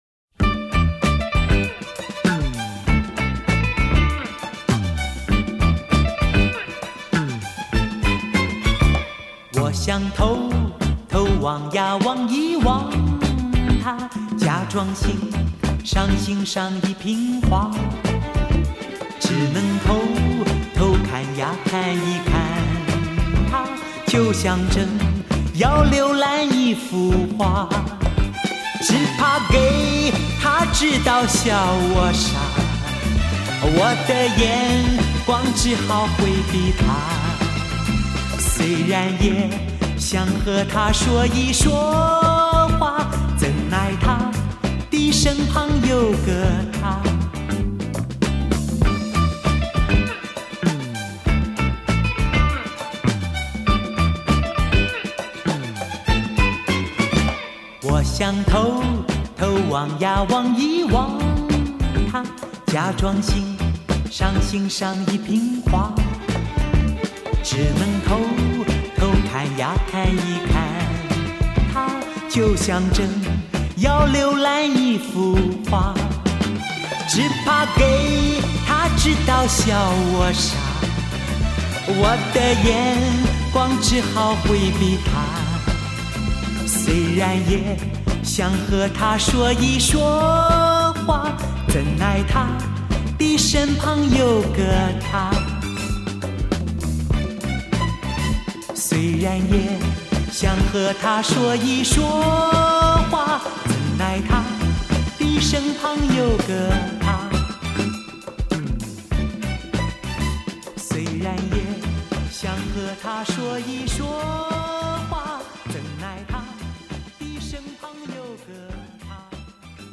2.现场模拟录音，1：1的直刻母带技术。